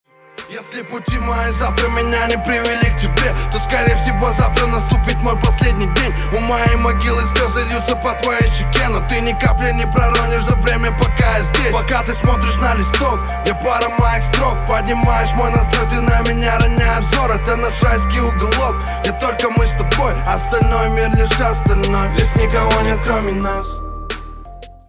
• Качество: 320, Stereo
Хип-хоп
русский рэп
лиричные